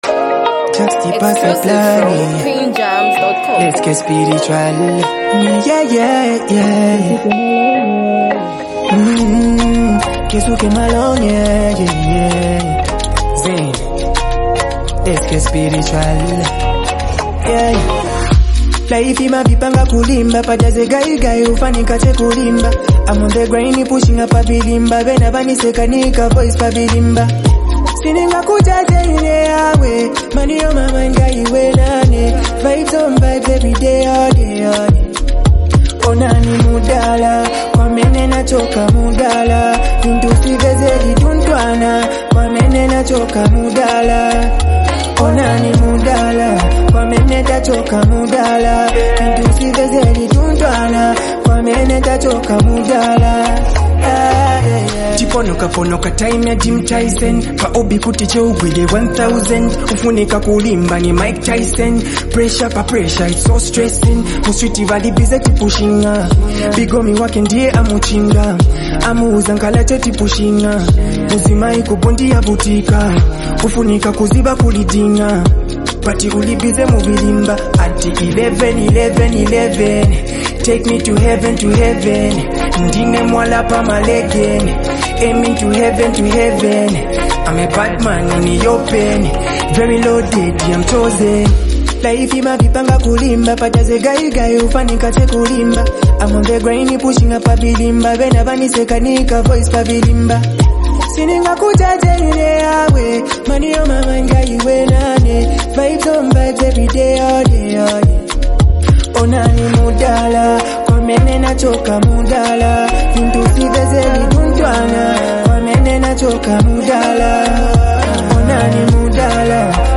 vocal delivery carries both vulnerability and confidence